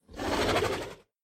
sounds / mob / horse / zombie / idle3.mp3